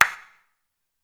Clap Rnb 1.wav